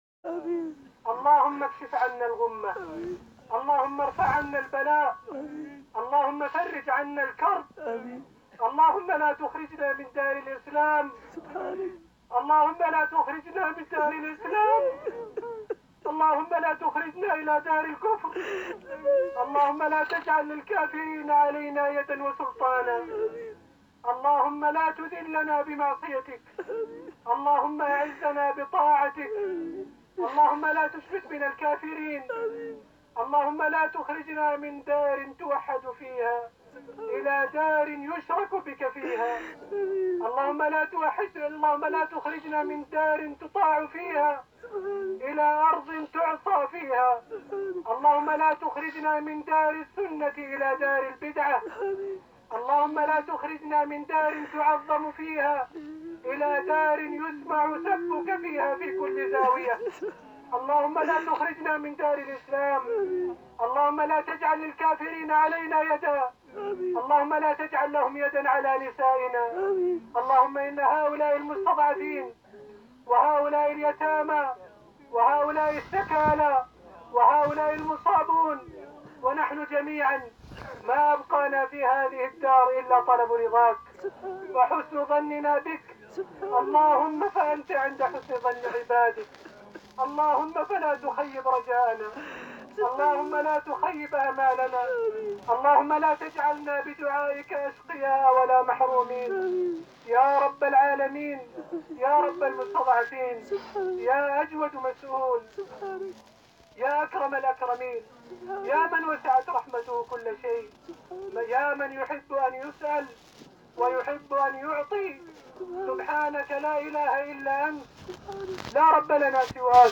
Боевики просят о помощи в коллективных мольбах.mp3